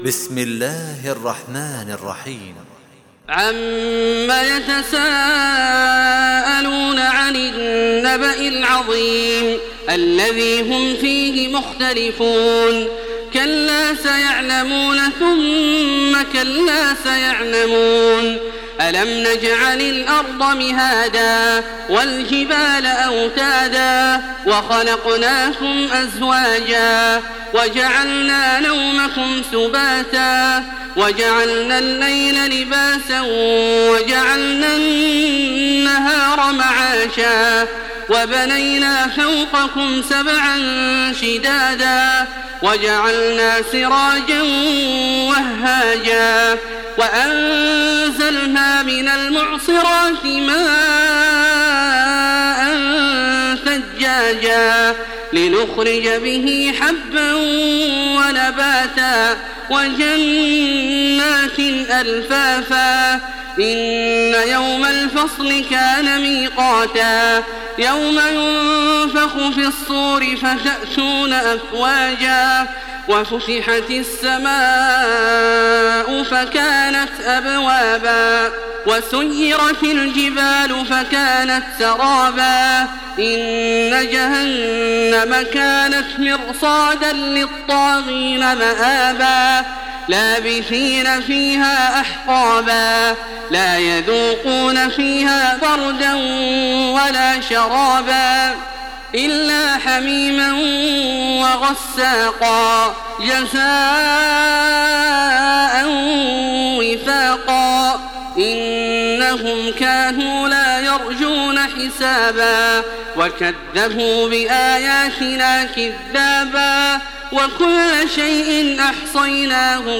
Surah An-Naba MP3 in the Voice of Makkah Taraweeh 1427 in Hafs Narration
Murattal Hafs An Asim